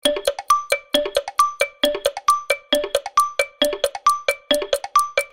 Download Free Cartoon Comedy Sound Effects | Gfx Sounds
Cartoon-timer-ticking-tick-tock-countdown.mp3